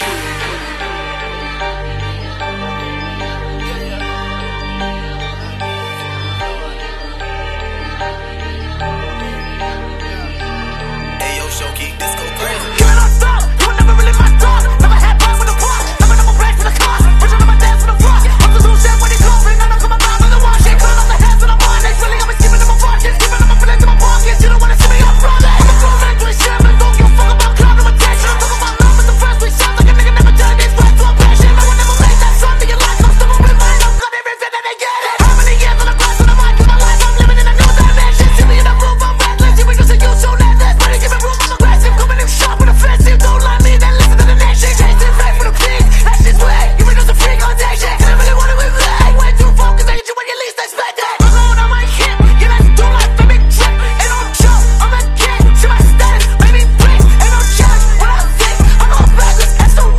trapmetal